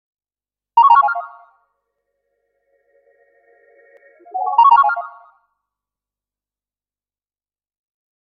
nokia-lumia-macro_24532.mp3